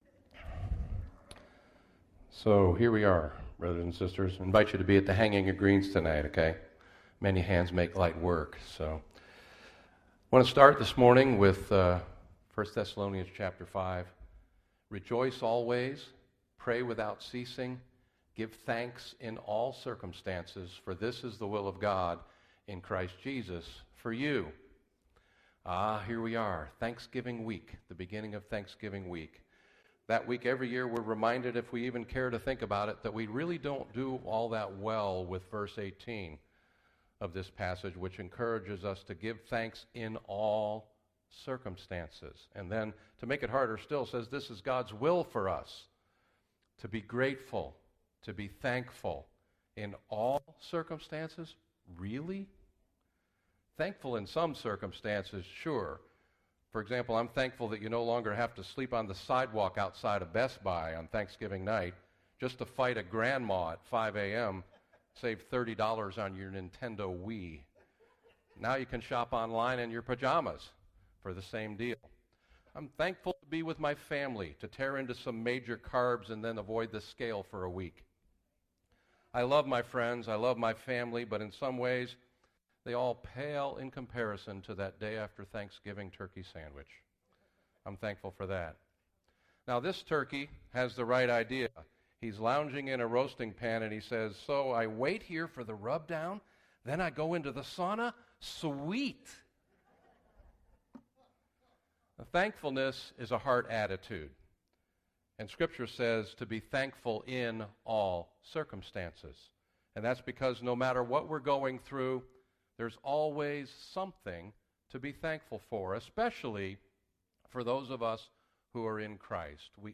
Tulsa Christian Fellowship Sermon Audio
TCF Sermons When You Need Them Most